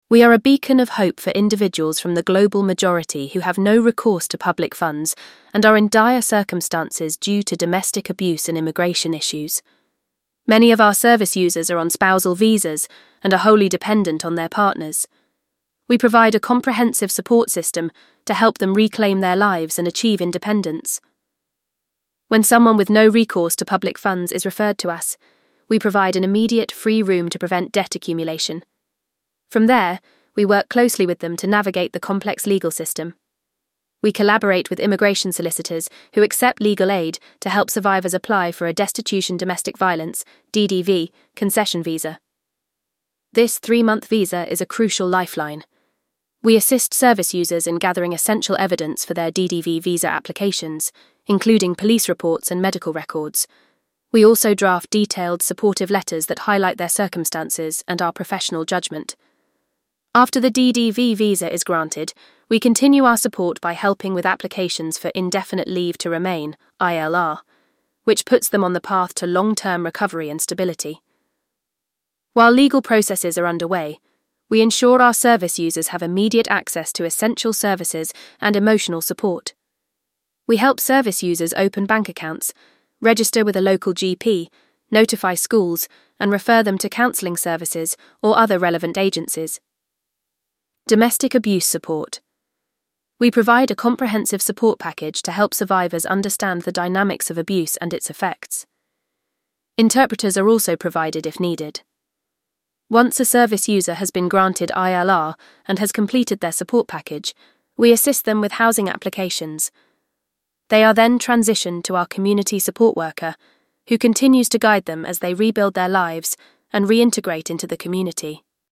VOICEOVER-Global-Majority.mp3